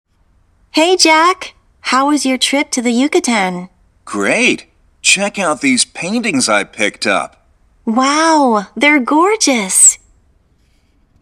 ◆ ＜リスニング＞They’re　英検準１級パート１過去問から抜粋
短縮形 They’re では、ゼイの「イ」は消えてなります。
ゼイアーではなく、ゼ。
音節は全部で3つです。
theyre-gorgeous_AE.m4a